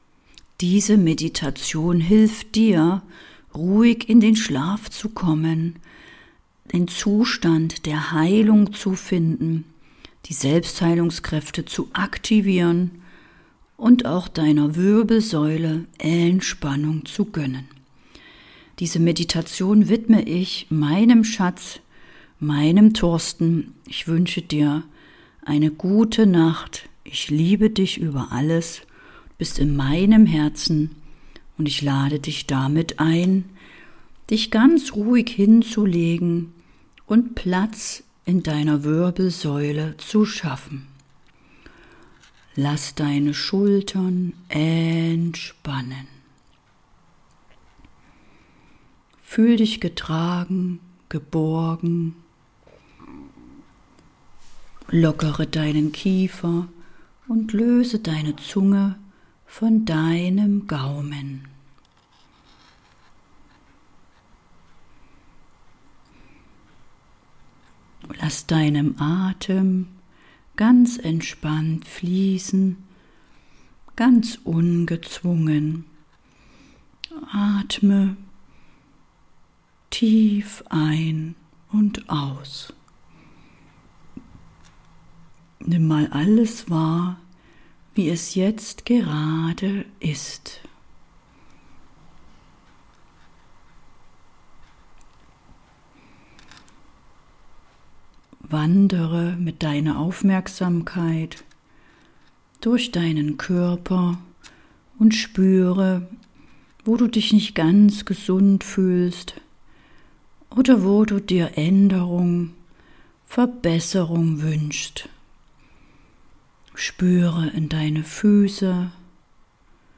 Einschlafmeditation zur Aktivierung der Selbstheilungskräfte I Ep. 193 ~ Erfolgstypen - mit den inneren Prinzipien zu mehr Erfolg im Außen Podcast